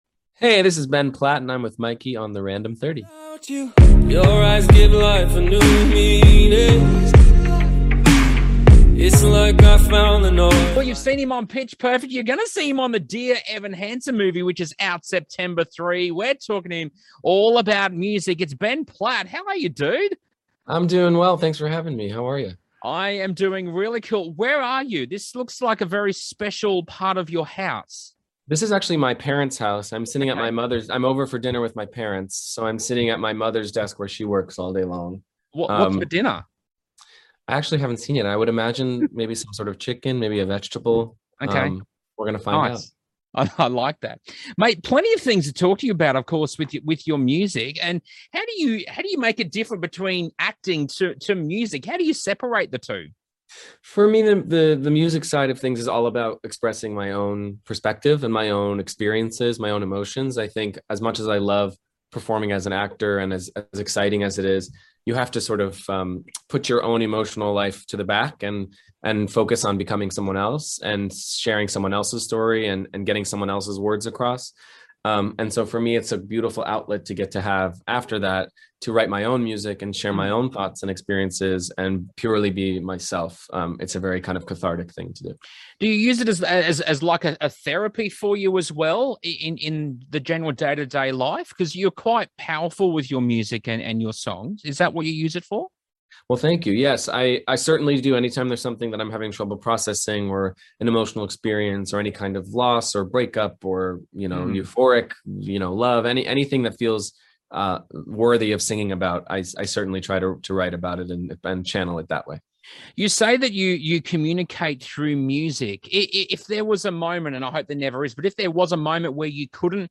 Ben Platt Interview